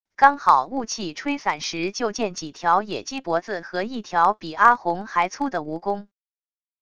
刚好雾气吹散时就见几条野鸡脖子和一条比阿红还粗的蜈蚣wav音频生成系统WAV Audio Player